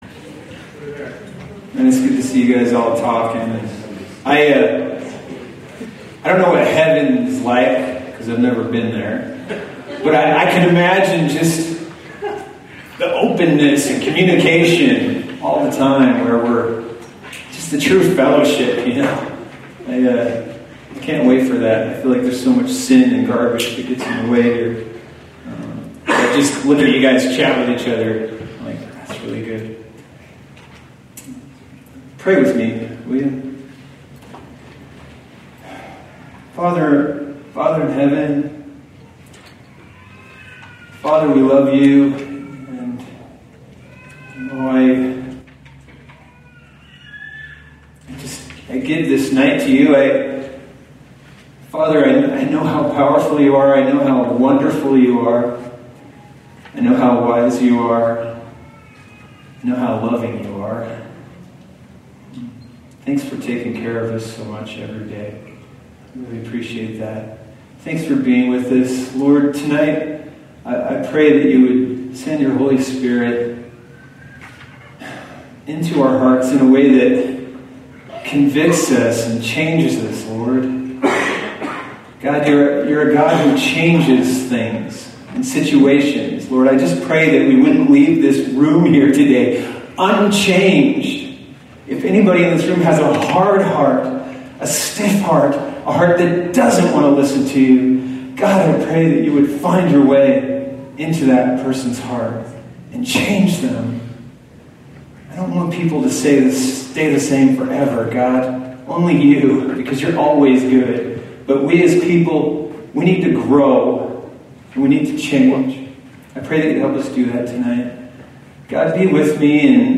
A message from the series "The Parables of Jesus ."